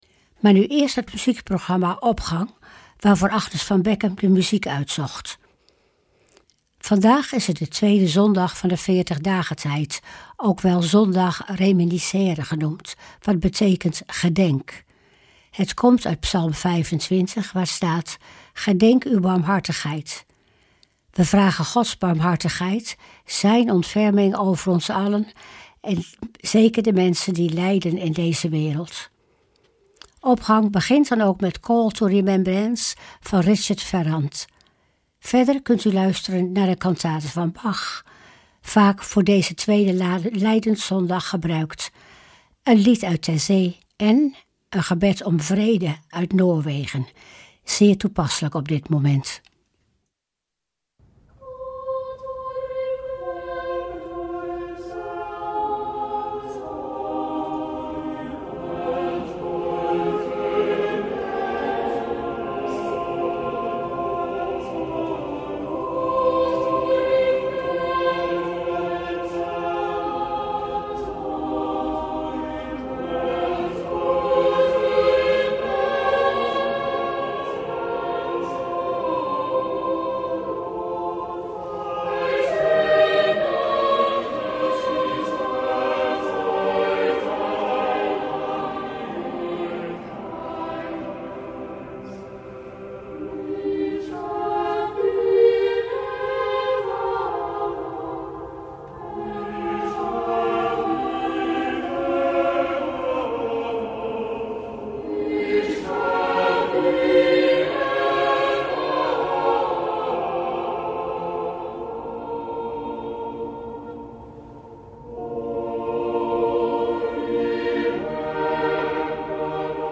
Opening van deze zondag met muziek, rechtstreeks vanuit onze studio.
Wij vragen Gods barmhartigheid, zijn ontferming, over ons en over allen die lijden in deze wereld. In de Opgang kunt u luisteren naar onder andere een Cantate van Bach, vaak voor deze tweede lijdenszondag gebruikt, een prachtig lied uit Taizé en een gebed om vrede uit Noorwegen.